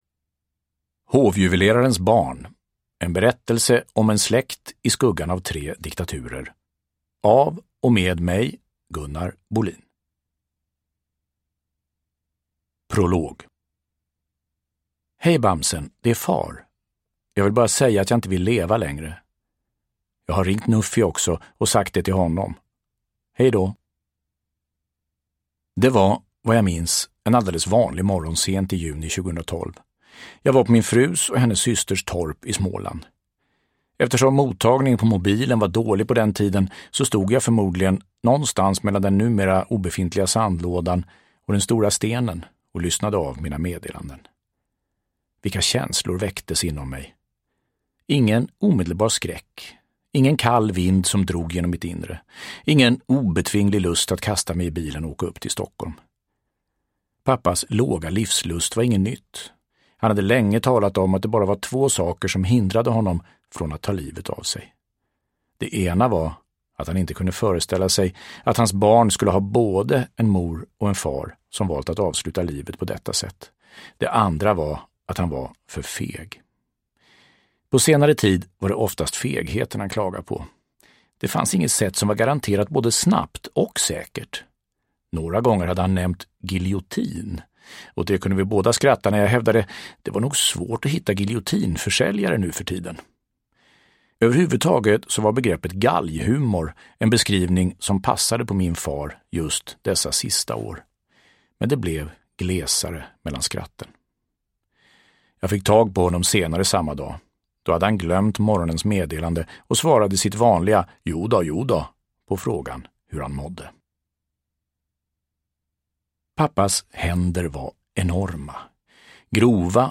Hovjuvelerarens barn : en berättelse om en släkt i skuggan av tre diktaturer – Ljudbok – Laddas ner